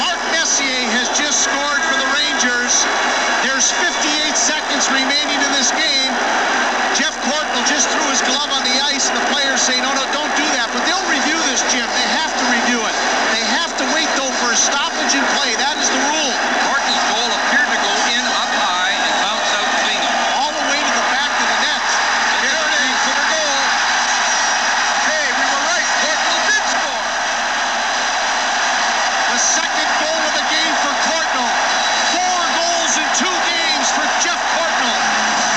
Download Part 3 of the dying moments of Game Six in Vancouver, the 'Best game ever played at the Pacific Coliseum.'